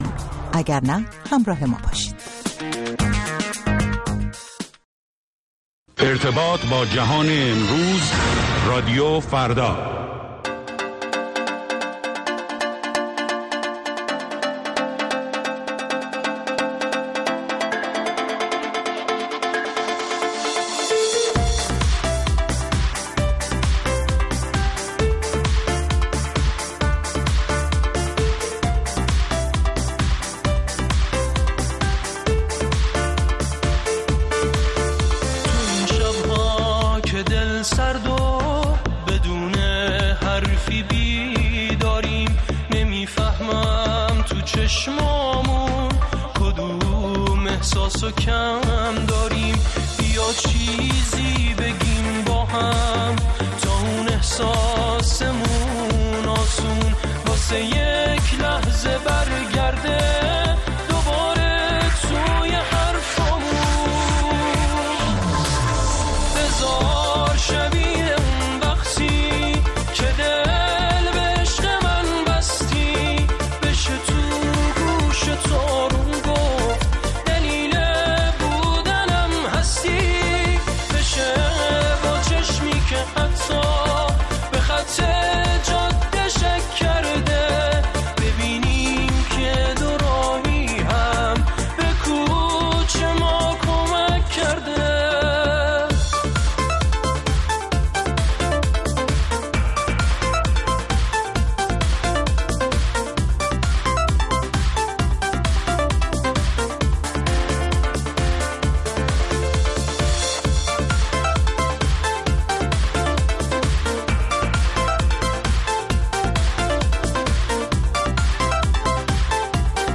اخبار شبانگاهی